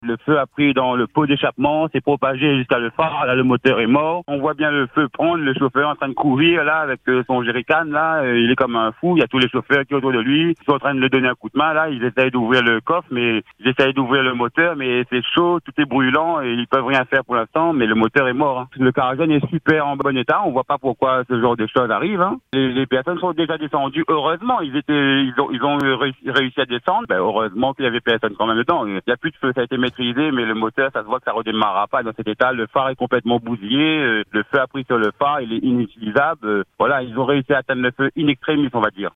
Nous vous proposons d’écouter le témoignage d’un auditeur présent au moment des faits, qui raconte ce qu’il a vu.